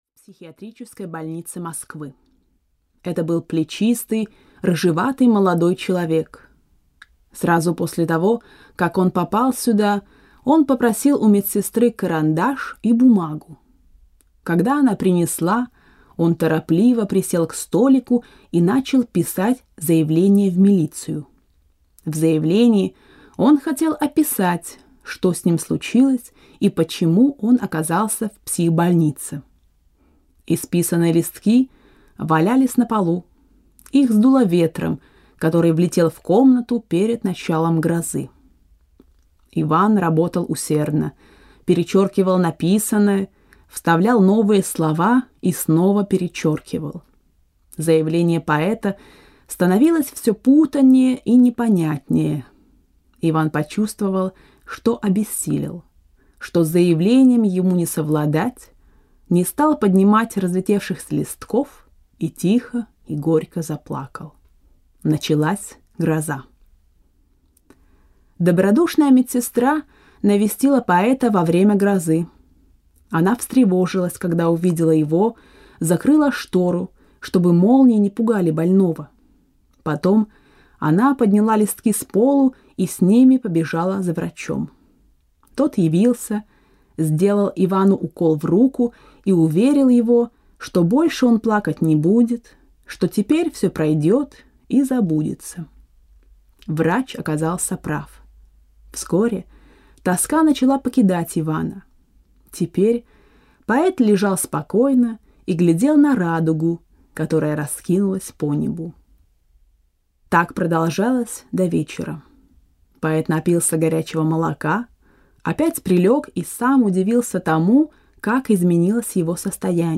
Master i Margarita (RUS) audiokniha
Ukázka z knihy